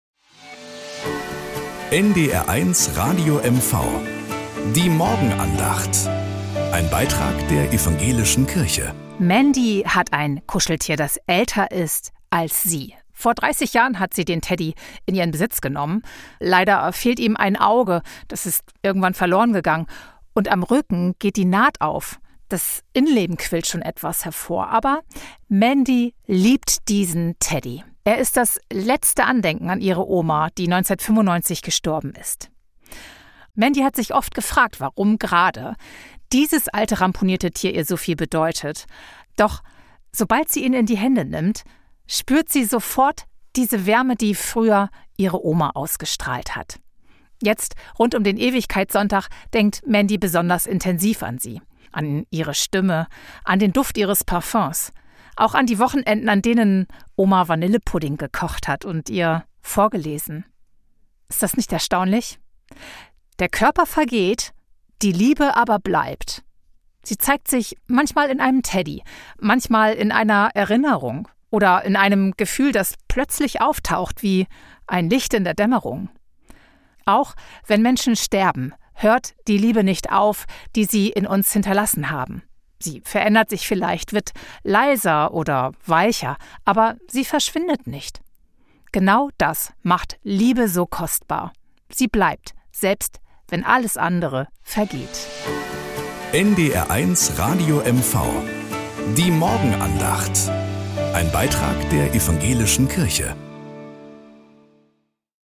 Morgenandacht bei NDR 1 Radio MV
Evangelische und katholische Kirche wechseln sich